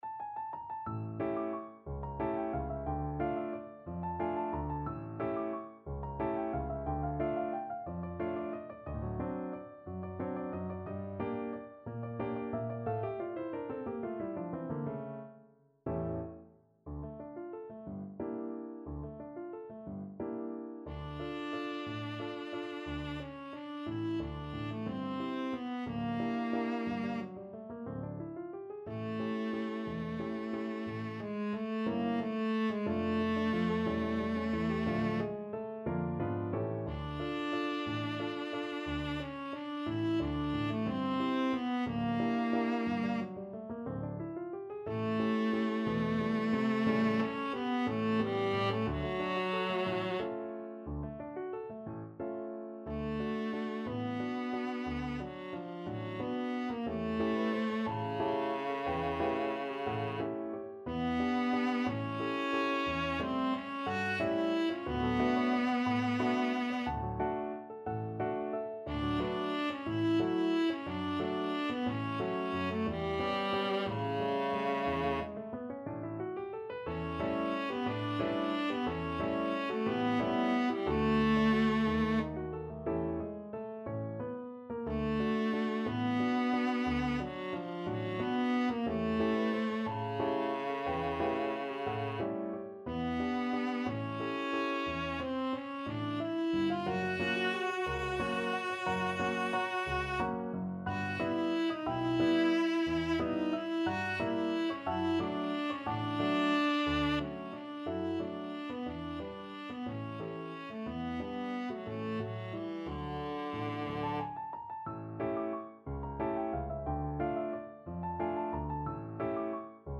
3/4 (View more 3/4 Music)
Allegro movido =180 (View more music marked Allegro)
Viola  (View more Easy Viola Music)
Classical (View more Classical Viola Music)